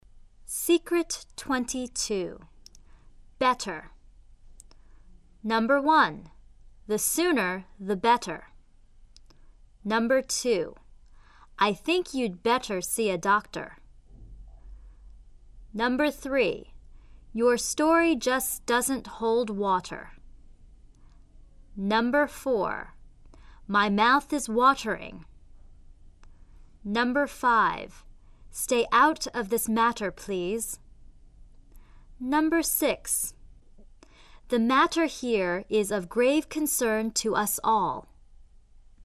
两个元音中间的[t]要浊化成[d]